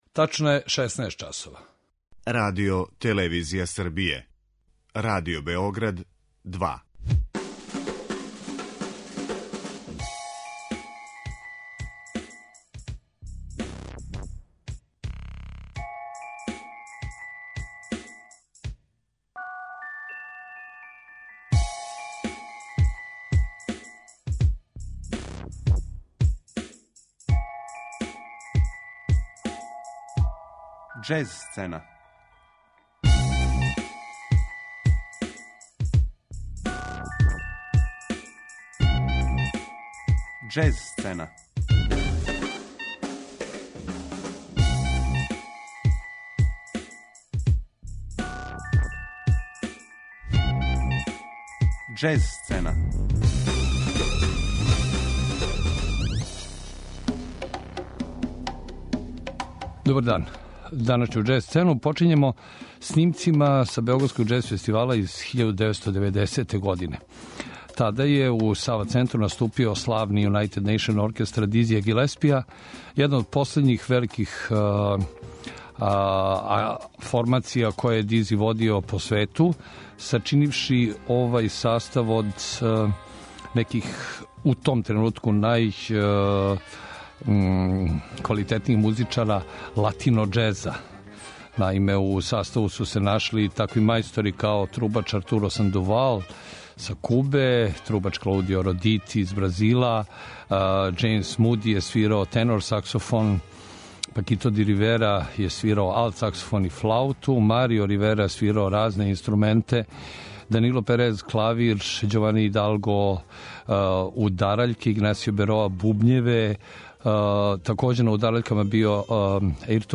У новом издању „Џез сцене“, слушамо избор антологијских снимака из Архиве Радио Београда са Београдског џез фестивала, настављајући серију осврта на нашу најстарију и интернационално најпризнатију џез манифестацију, поводом пола века од њеног оснивања.